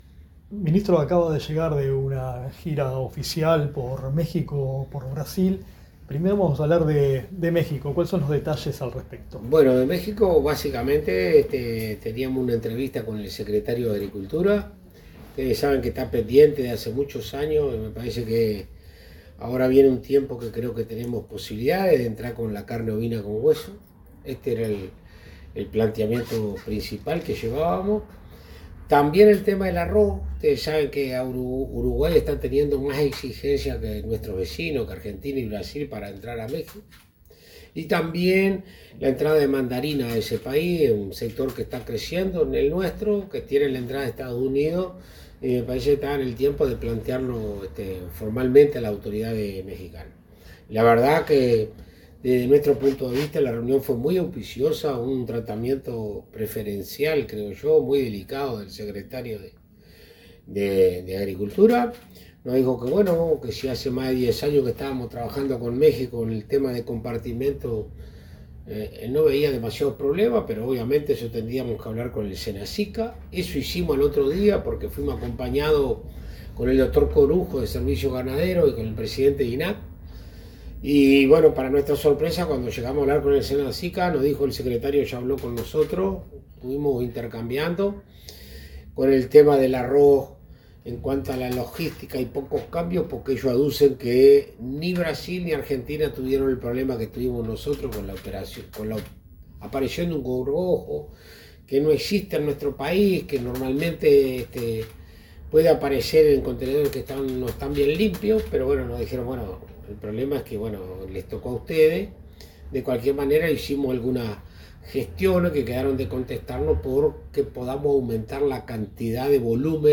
Tras una visita oficial a México y Brasil, el ministro de Ganadería, Agricultura y Pesca, Alfredo Fratti, realizó declaraciones a la prensa.